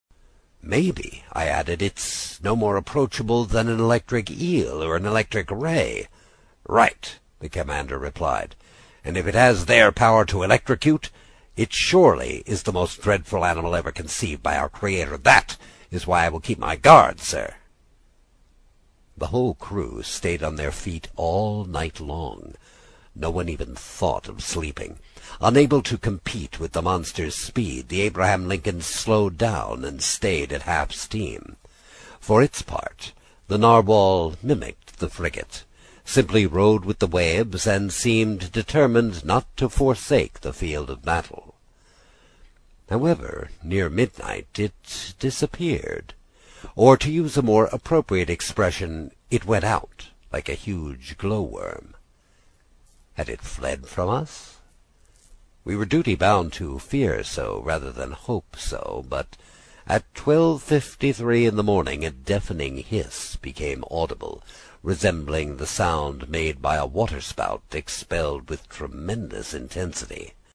英语听书《海底两万里》第63期 第6章 开足马力(5) 听力文件下载—在线英语听力室